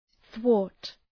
Προφορά
{twɔ:rt}